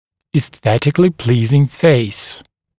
[iːs’θetɪklɪ ‘pliːzɪŋ feɪs] [и:с’сэтикли ‘пли:зин фэйс] привлекательное лицо, эстетически приятное лицо (US esthetically pleasing face)